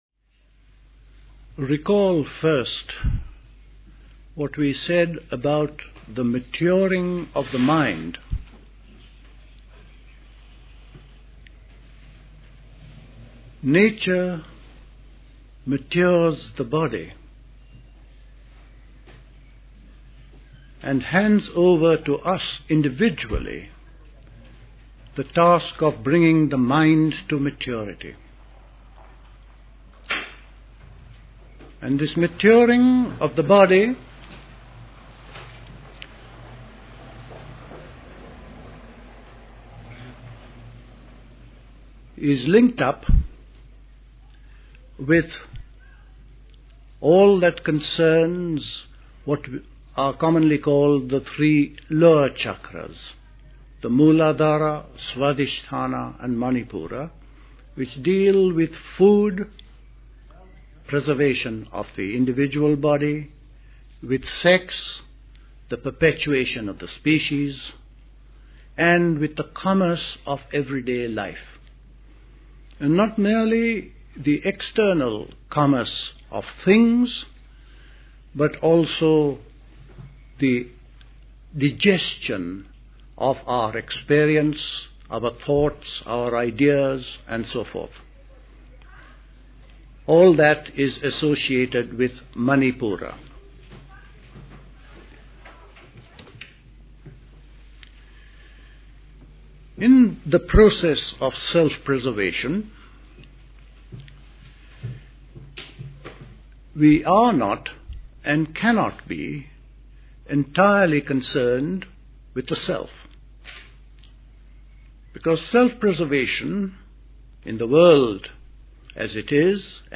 A talk
Recorded at the 1974 Park Place Summer School.